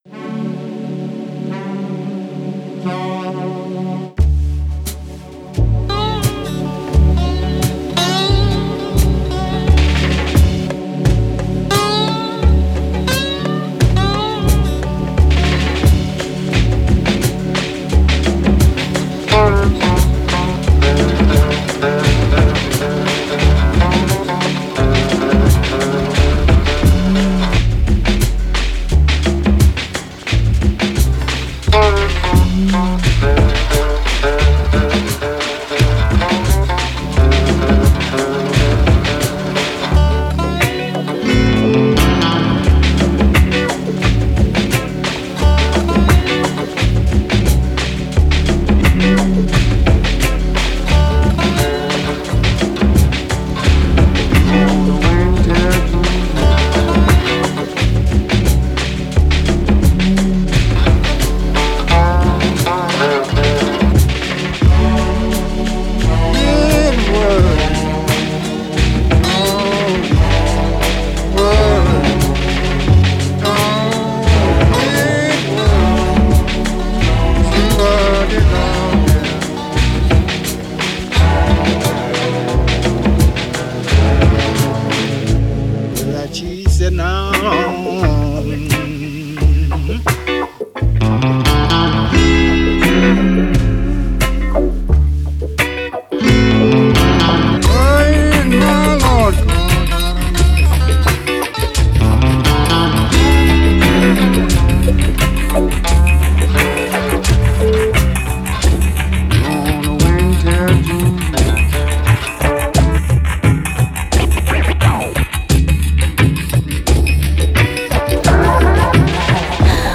Genre: Lounge, Downtempo.